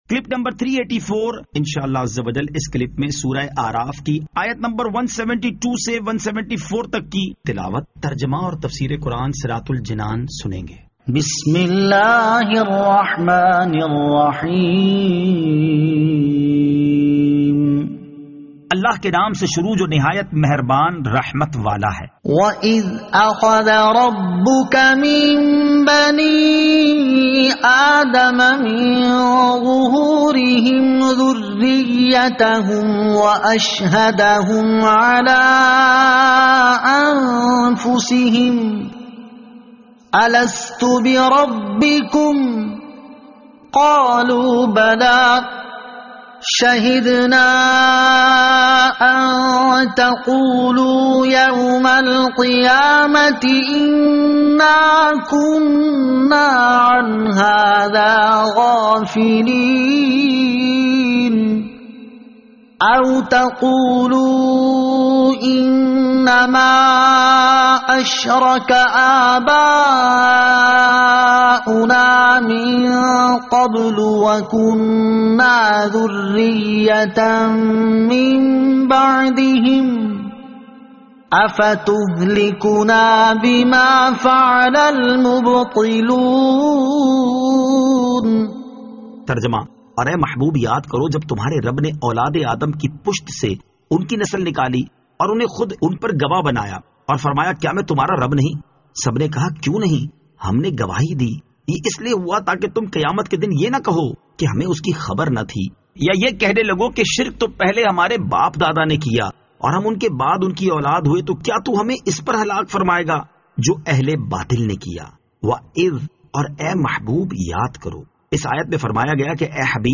Surah Al-A'raf Ayat 172 To 174 Tilawat , Tarjama , Tafseer